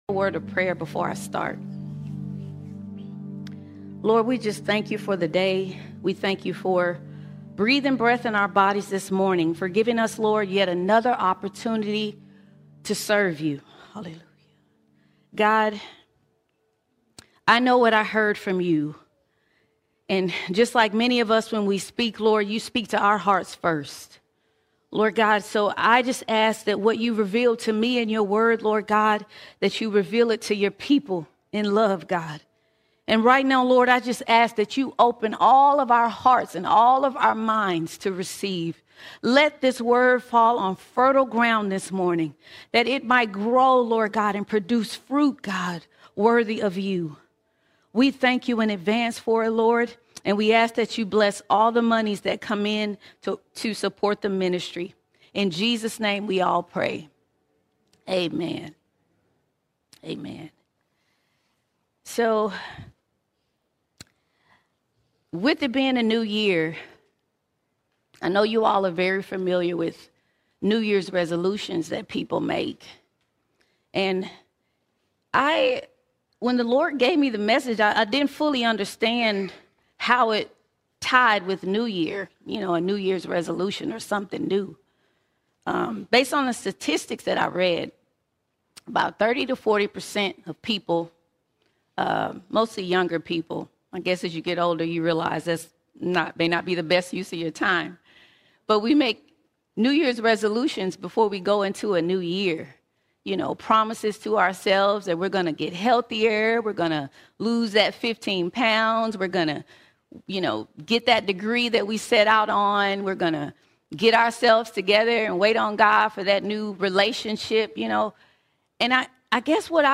12 January 2026 Series: Sunday Sermons All Sermons Rekindle The Fire Rekindle The Fire God is a consuming fire, not a passing flame.